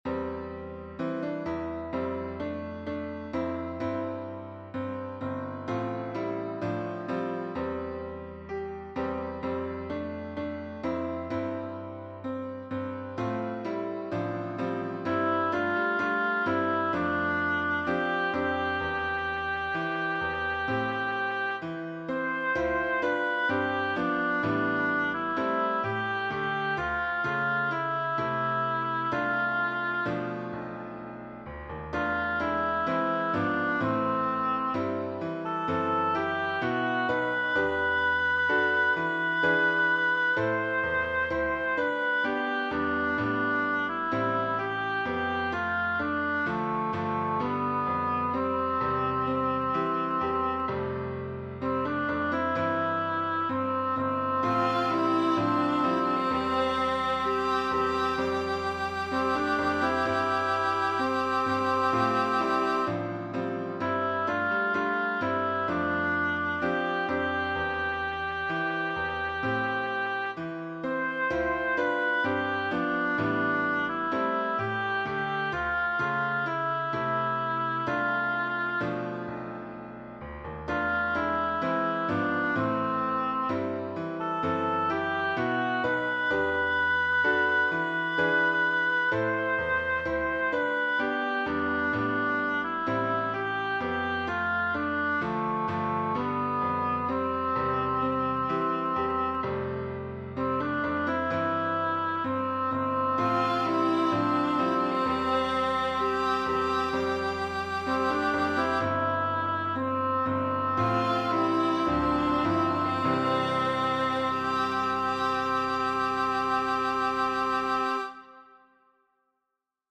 A slow gospel-style tune